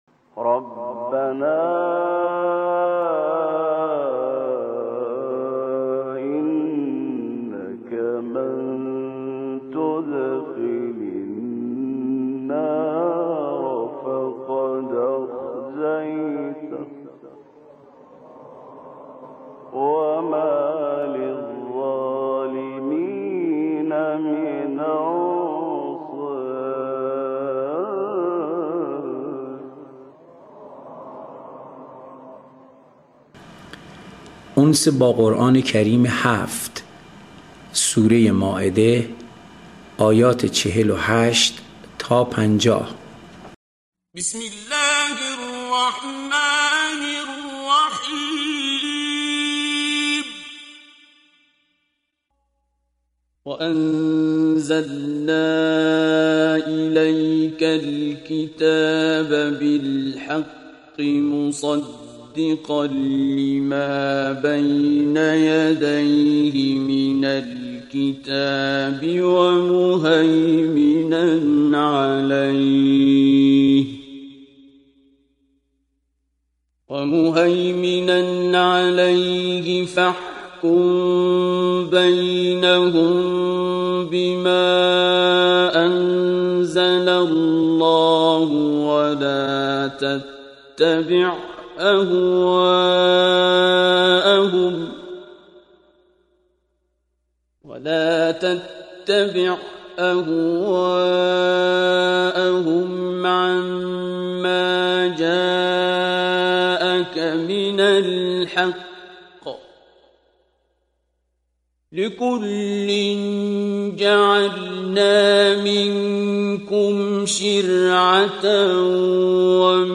قرائت آیات آیات 48 و 49 سوره مائده